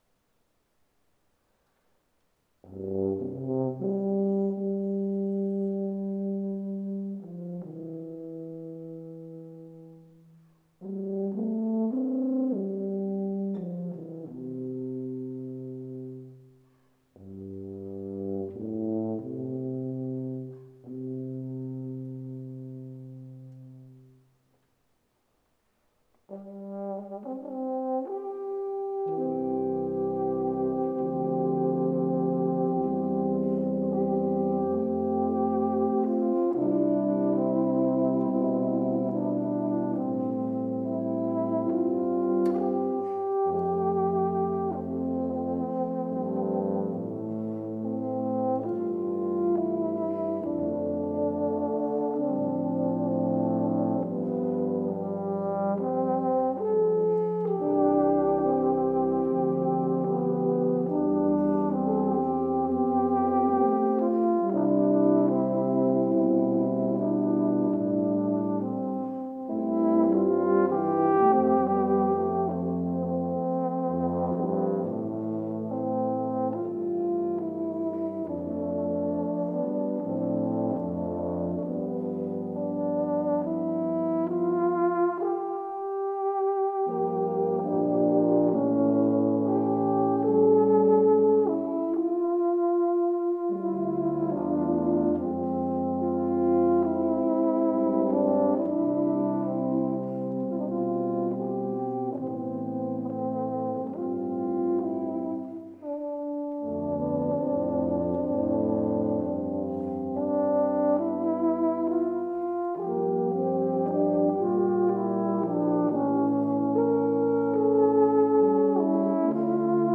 Sample #1: Tuba and Euphonium Ensemble (03:25) (27MB/file).
B-format files for the experimental and Soundfield MkV mics.